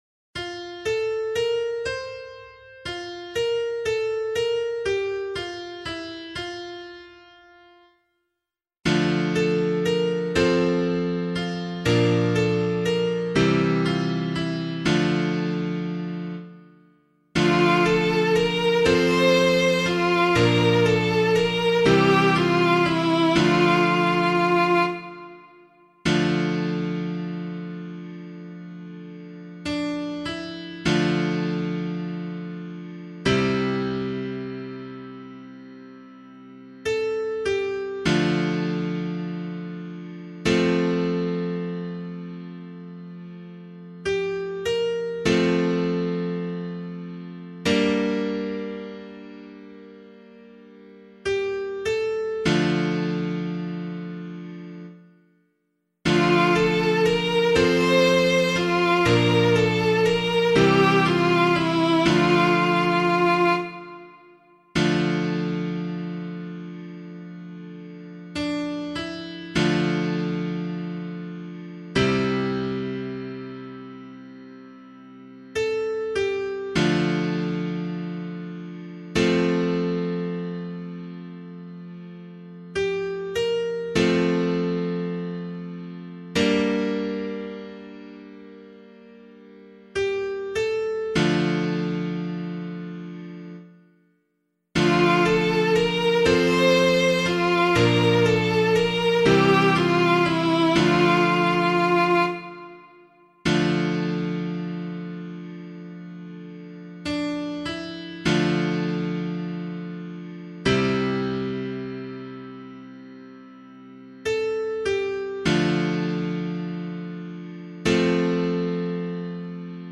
040 Ordinary Time 6 Psalm A [LiturgyShare 1 - Oz] - piano.mp3